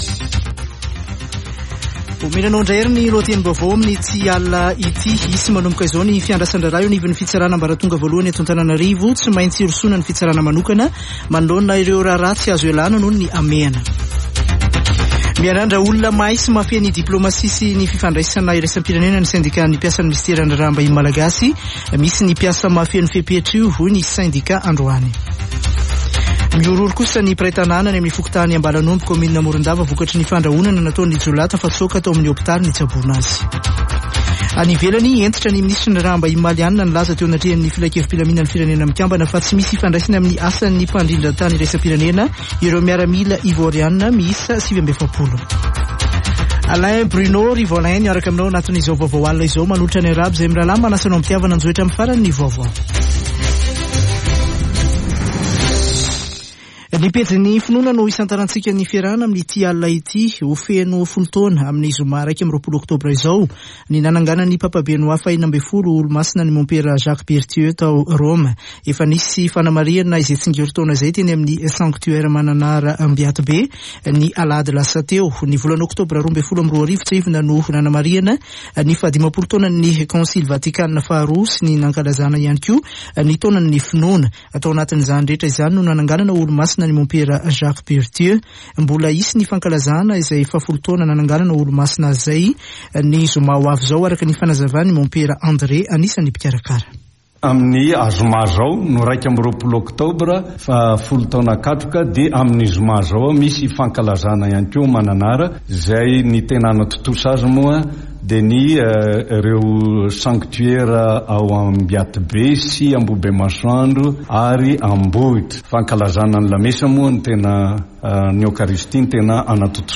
[Vaovao hariva] Alarobia 19 ôktôbra 2022